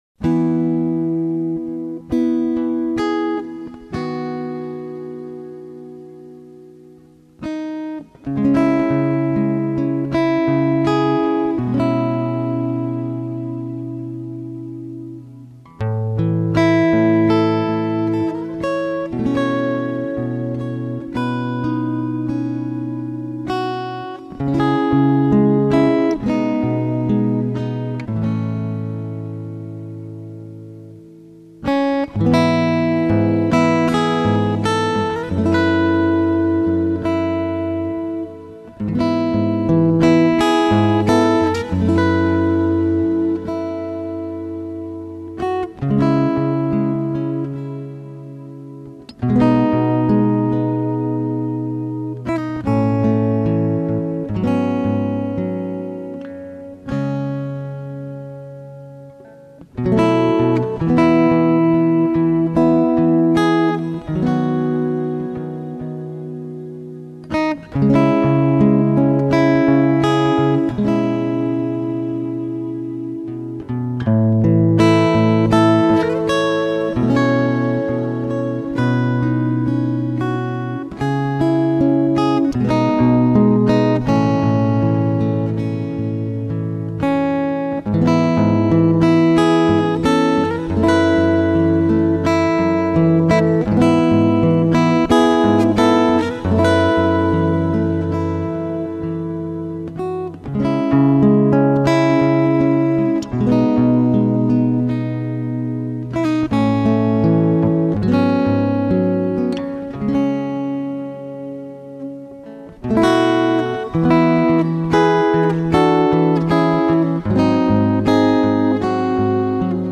很喜欢这张精选，喜欢编者挑选曲目的精心安排，全然的舒服和放松，
感觉悠然如同穿梭于浮云间的自由，怎么样？
一切就有这种音乐来托起，没有负担，没有压力。